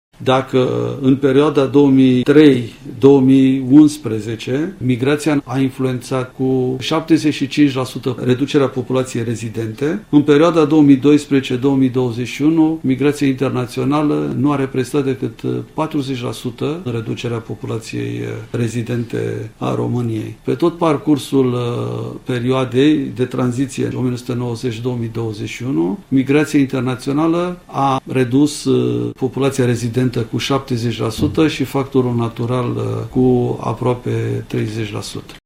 În ceea ce priveşte reducerea populaţiei, principalul factor este migraţia internaţională, însă ponderea fenomenului este în scădere – a explicat Tudorel Andrei, preşedintele Institutului Naţional de Statistică: